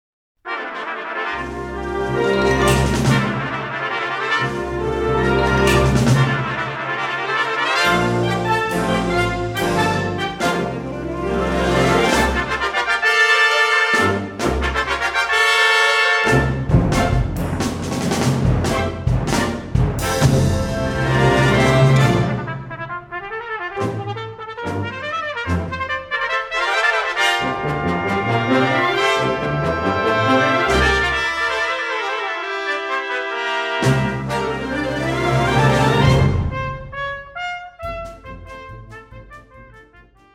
5 trompettes et Orchestre d'Harmonie (11'26)
* Studio DAVOUT.